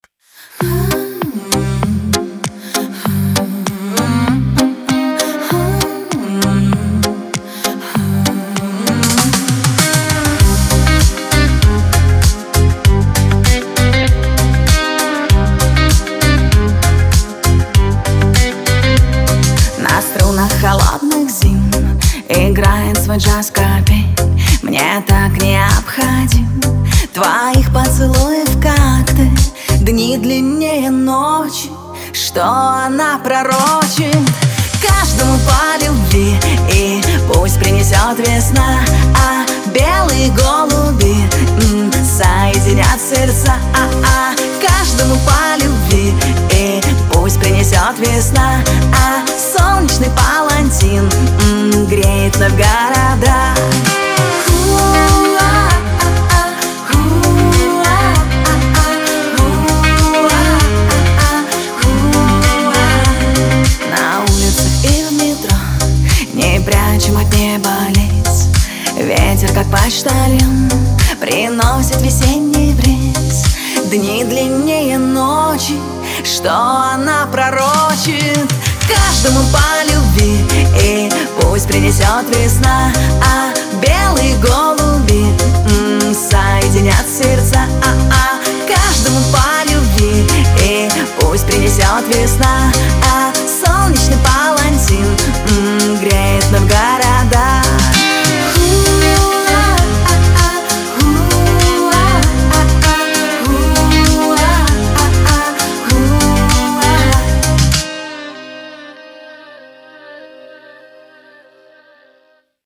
pop , эстрада , диско